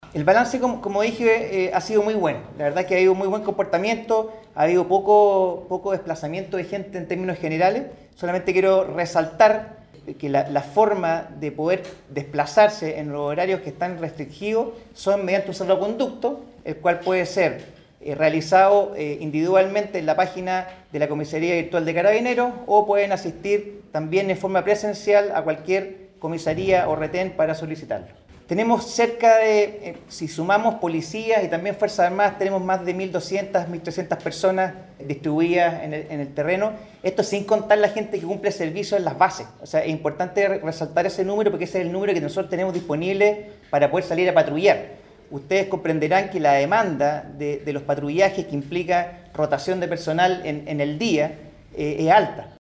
Finalmente, y en materia de seguridad, el jefe de la Defensa Nacional en Biobío, contralmirante Jorge Keitel, comentó que “los patrullajes que realizamos son diurnos y nocturnos, es decir, no solamente nos enfocamos en el toque de queda”, agregando que las ocho comunas informadas la semana pasada se mantienen en toque de queda.